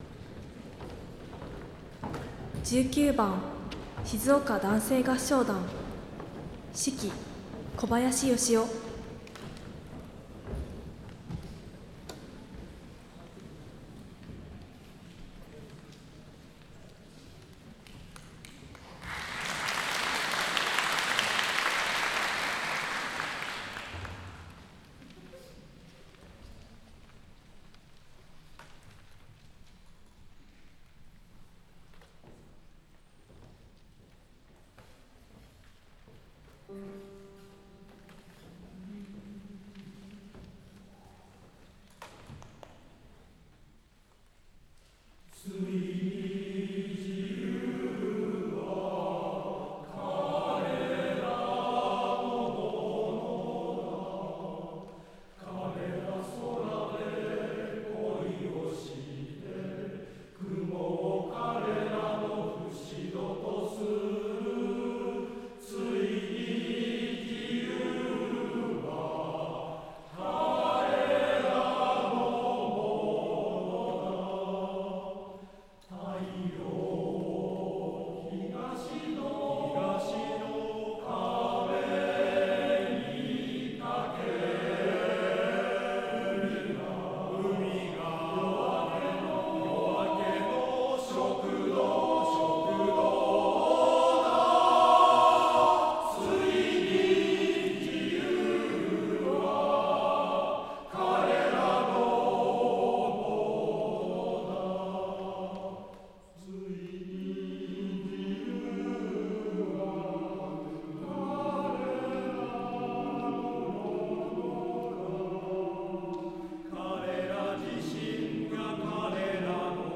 6/2 第88回合唱のつどい